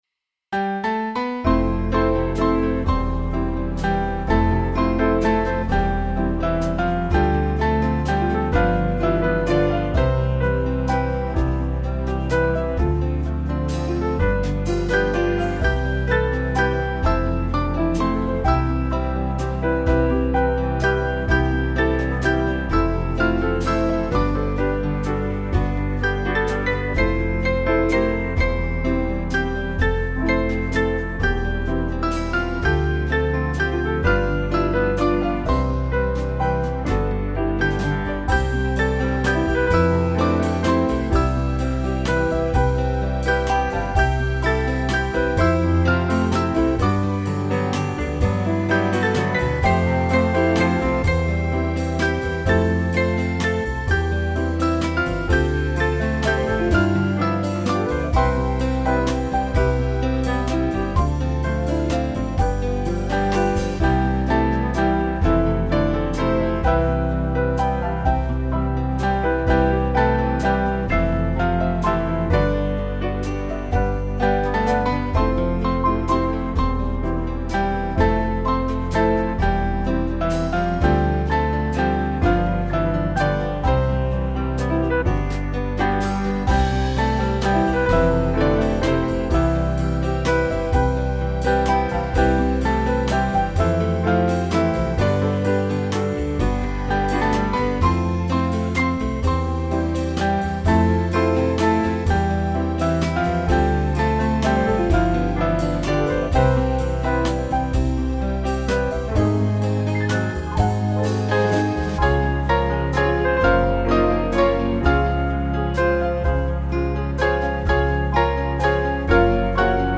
Small Band
Slow Waltz Rhythm